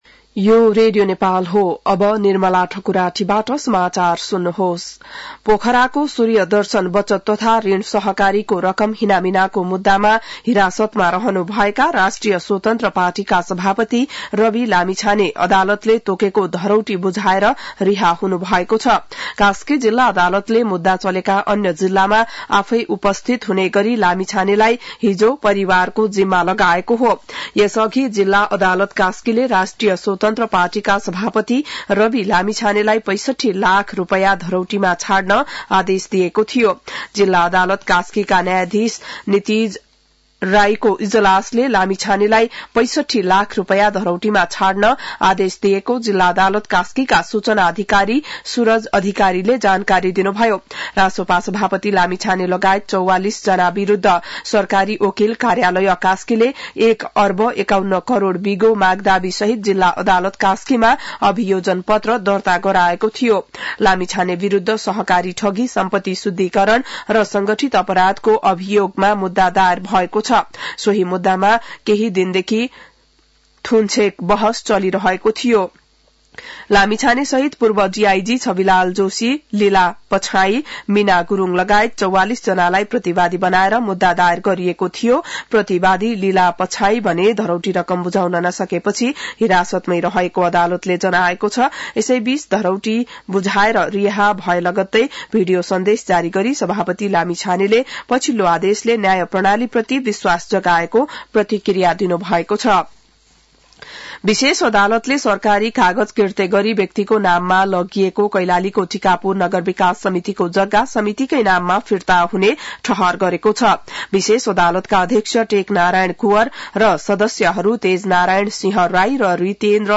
An online outlet of Nepal's national radio broadcaster
बिहान १० बजेको नेपाली समाचार : २७ पुष , २०८१